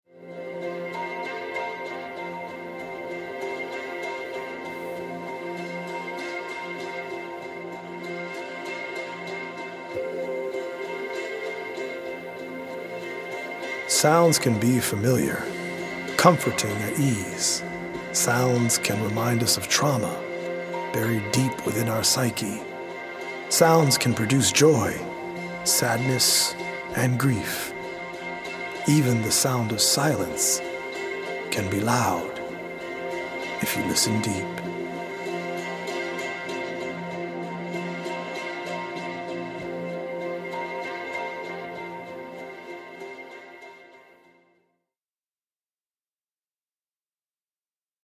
audio-visual poetic journey
healing Solfeggio frequency music
EDM producer